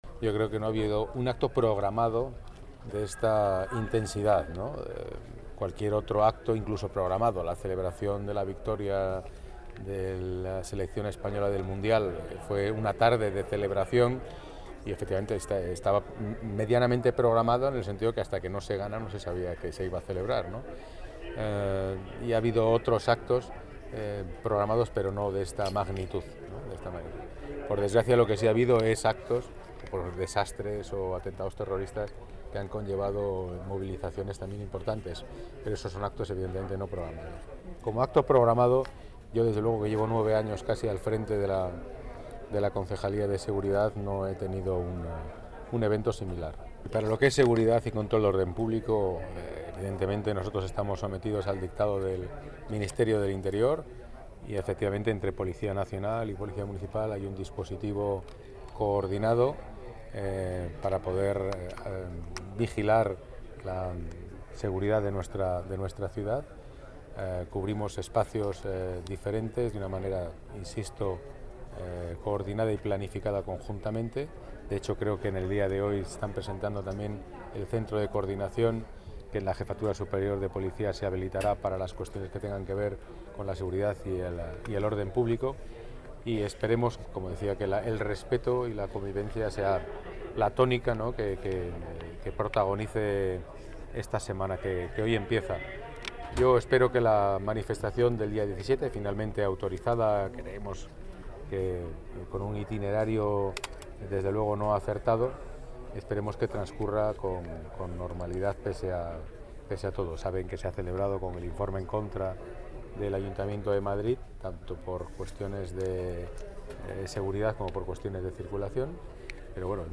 Nueva ventana:Pedro Calvo explica la coordinación de emergencias durante la JMJ